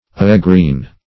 Ayegreen \Aye"green`\, n. [Aye ever + green.] (Bot.)